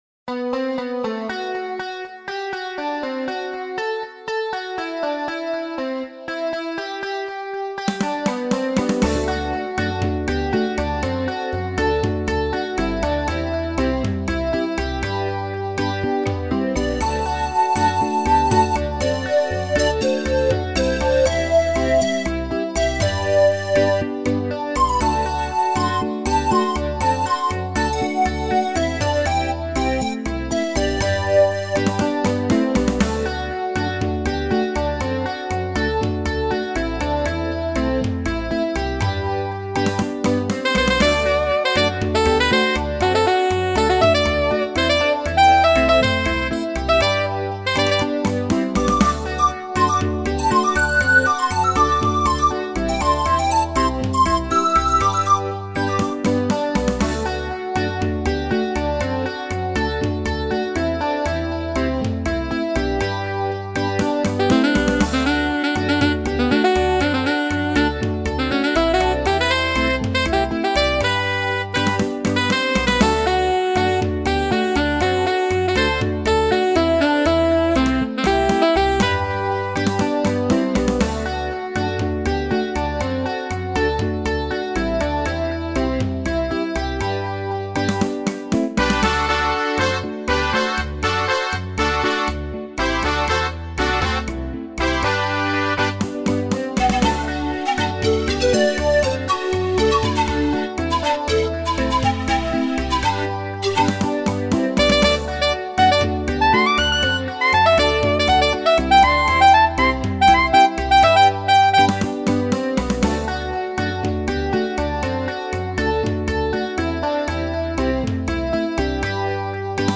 African Pop in Angolan Kizomba style.
Afropop (Kizomba/Angola)